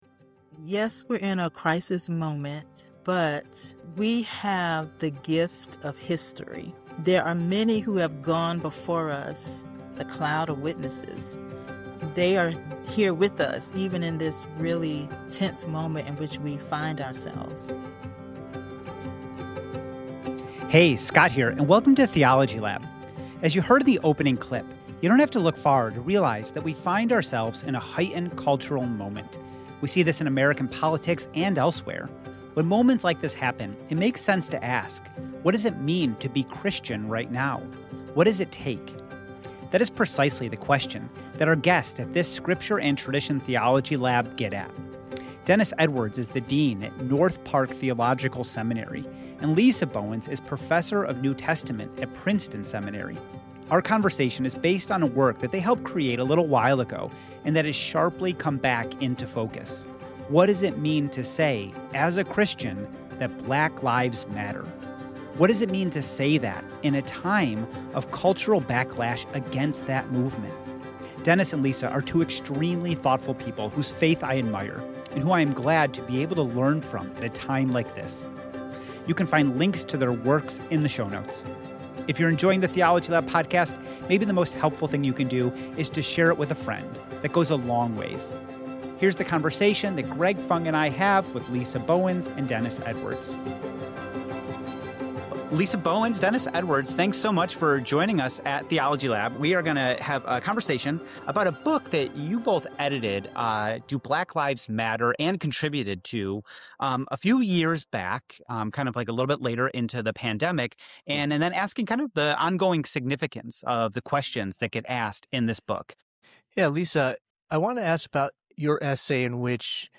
Theology Lab interview